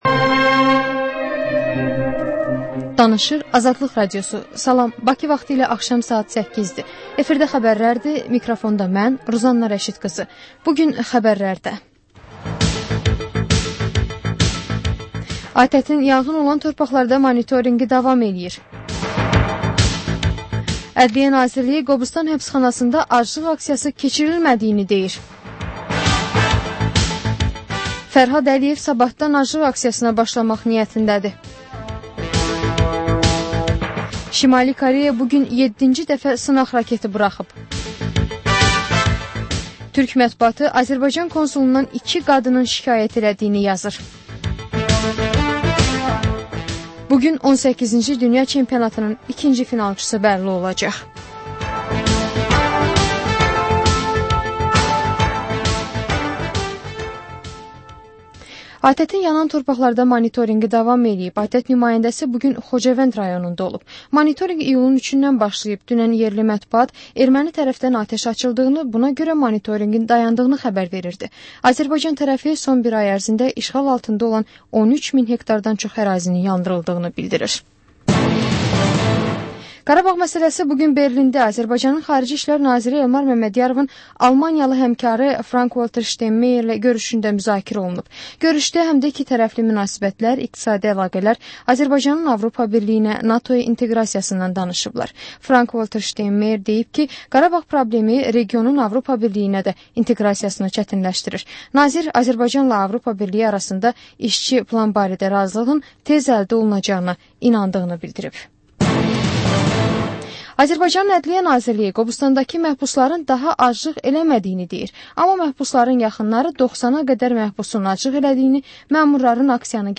Xəbər, reportaj, müsahibə. Sonra: Kontekst: Təhlil, müsahibə və xüsusi verilişlər.